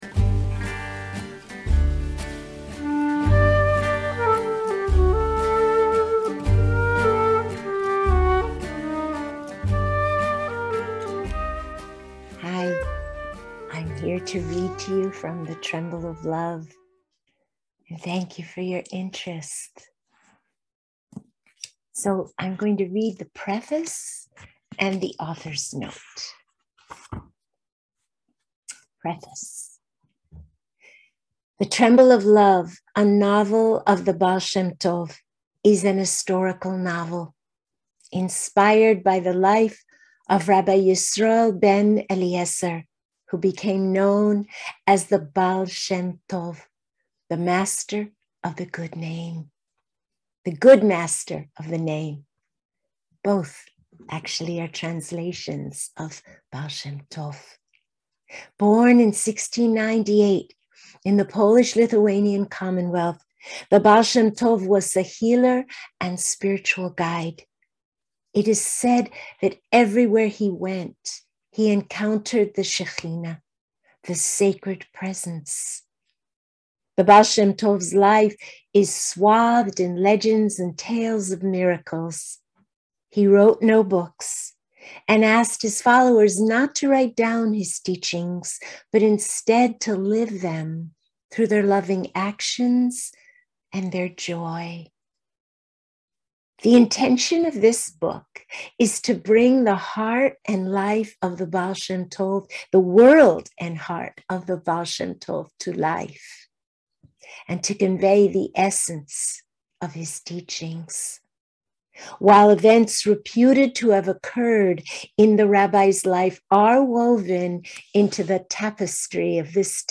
Your voice is so soothing, it just makes me want to take a deep breath, relax, and be read to!
Your voice is beautiful too, and your connection to the Love we are talking about is clear.